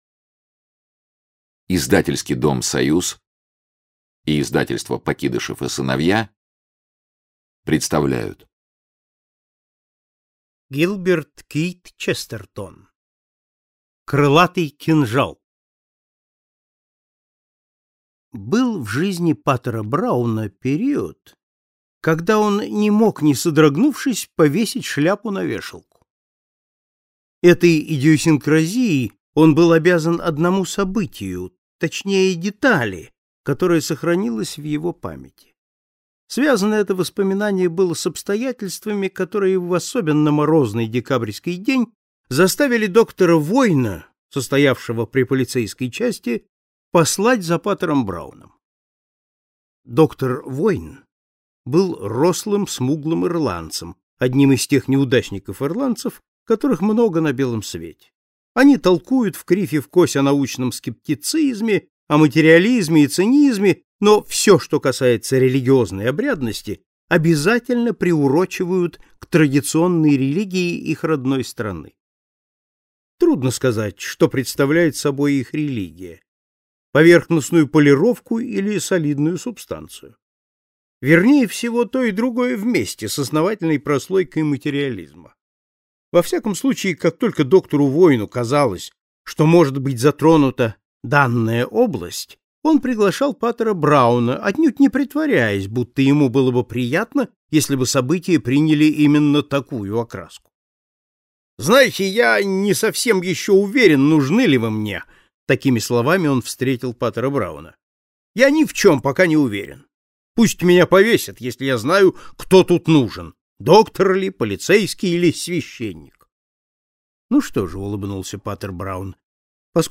Аудиокнига Крылатый кинжал | Библиотека аудиокниг